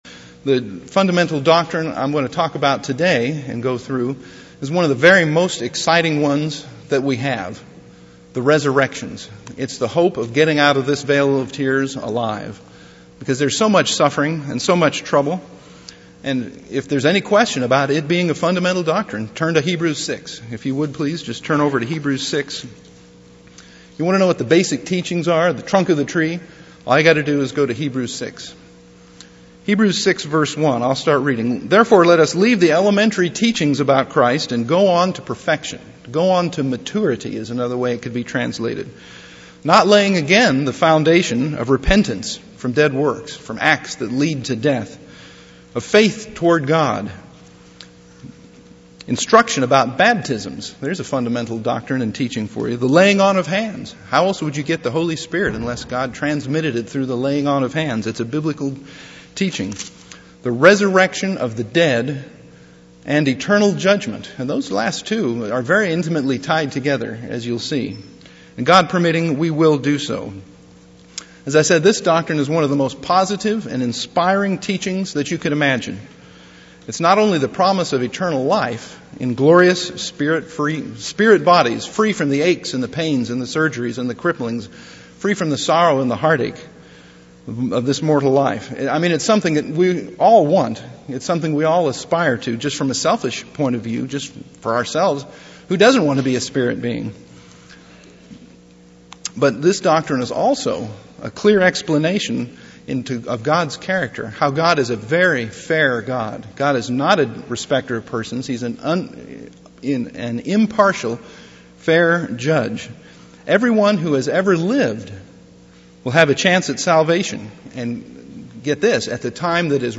[Note: The recording device unexpectedly shut off about 10 minutes before the end of the message.]
UCG Sermon Studying the bible?